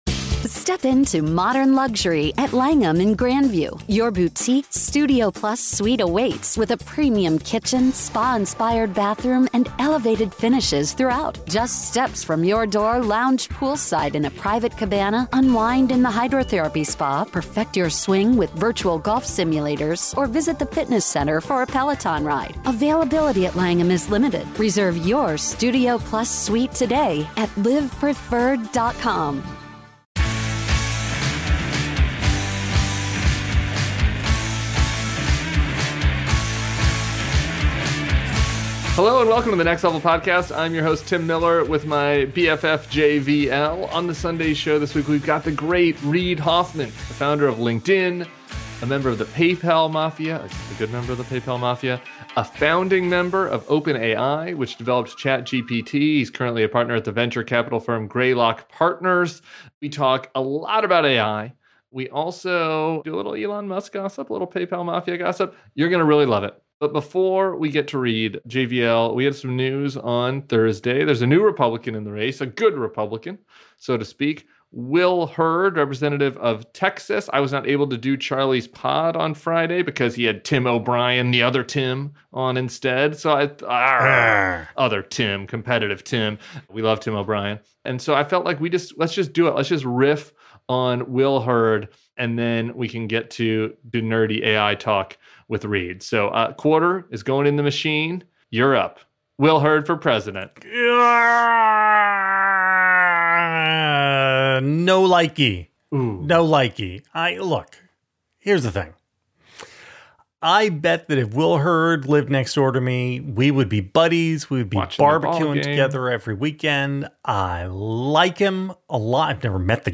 interview Reid Hoffman, a founding member of OpenAI, aka the creators of ChatGPT. Together they discuss how the future of humanity could unfold as artificial intelligence becomes more and more advanced. They also talk Elon Musk's venture into politics and the possible reason why he made such a choice.